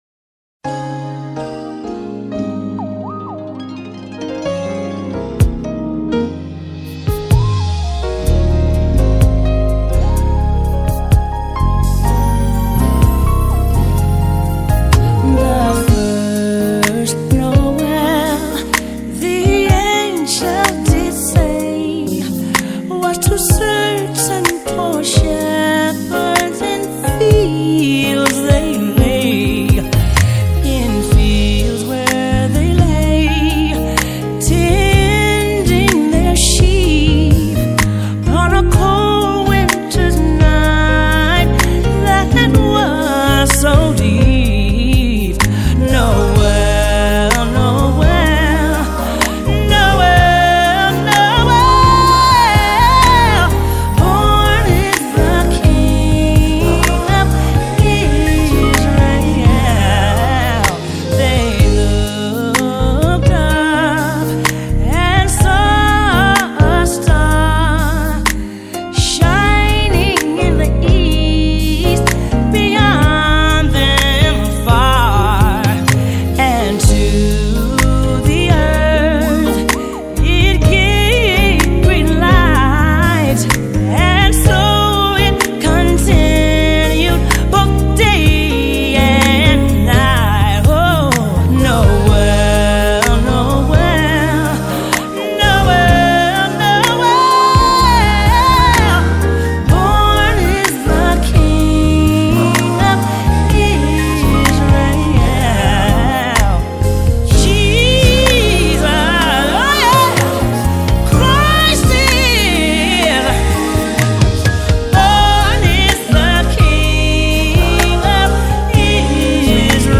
Holiday, Pop, R&B